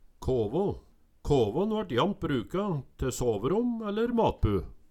kåvå - Numedalsmål (en-US)